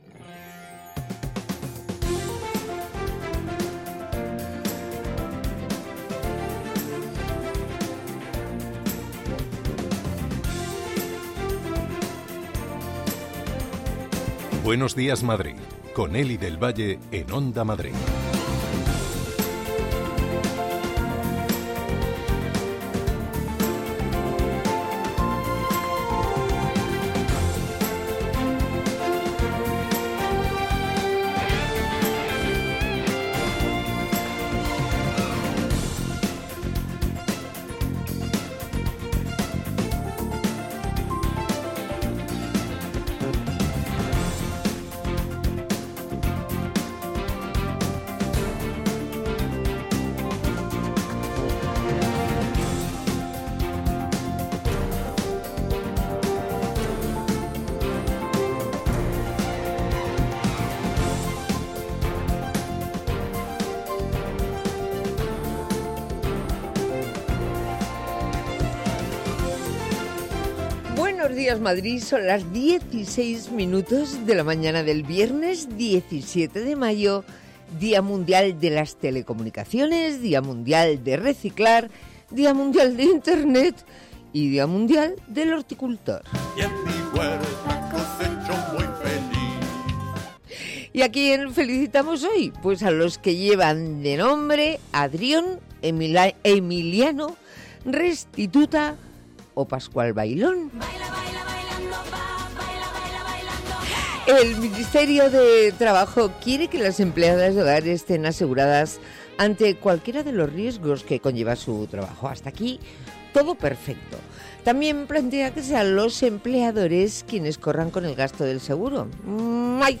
Tres horas más de radio donde se habla de psicología, ciencia, cultura, gastronomía, medio ambiente y consumo.